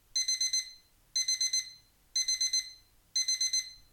Broccoli Blanched with Sesame Oil ブロッコリーの塩ごま油茹で [alarm].mp3